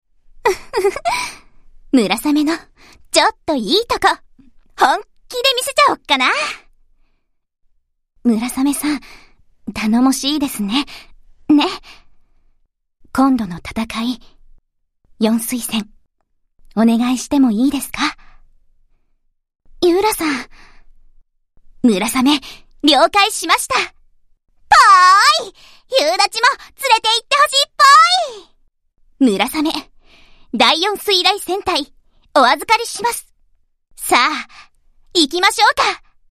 Here are the voice lines played upon completion of certain quests.